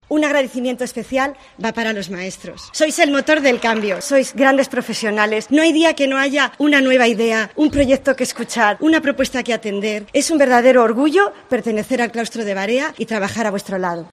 muy emocionada